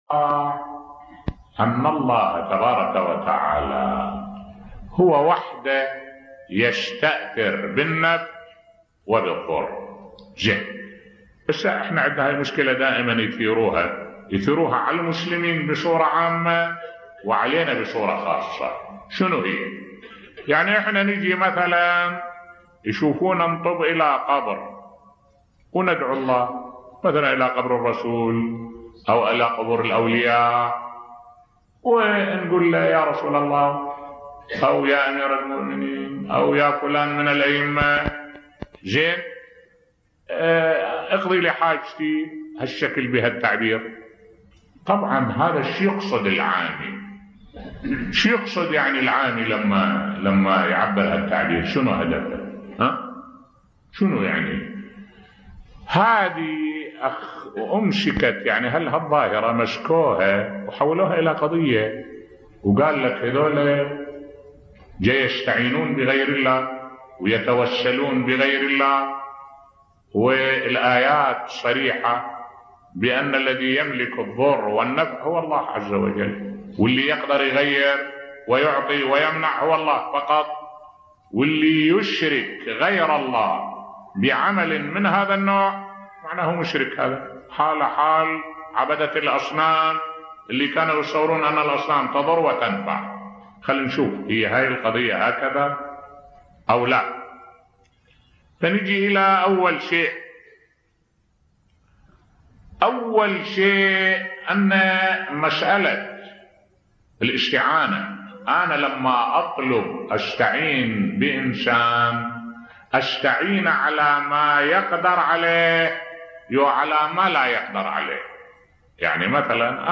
ملف صوتی التوسل والاستغاثة بصوت الشيخ الدكتور أحمد الوائلي